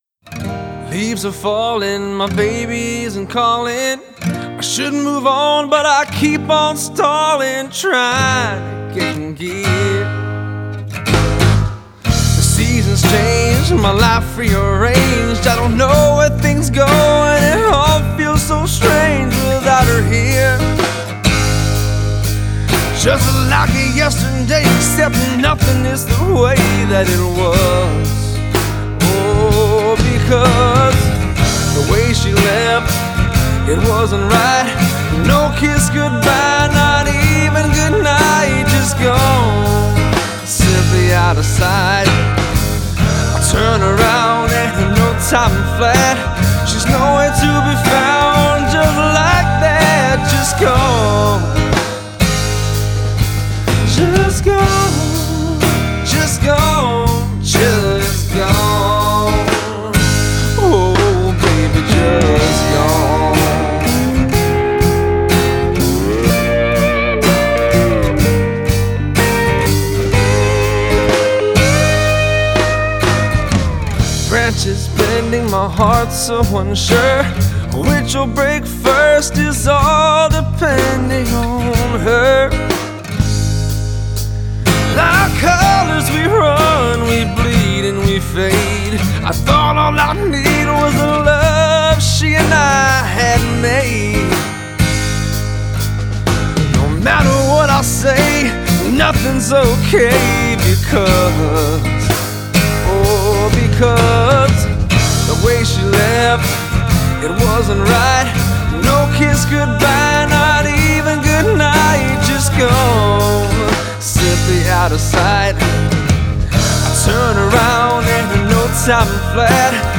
slide guitar